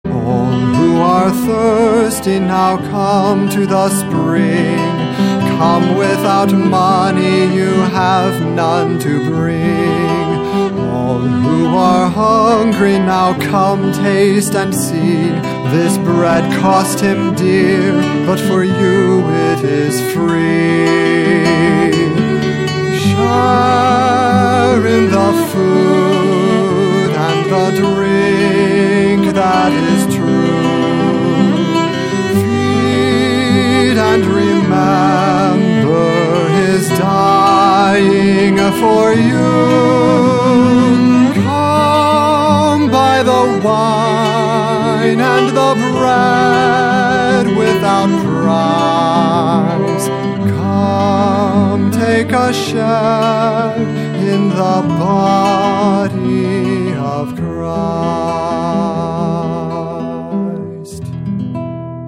Songs for Voice & Guitar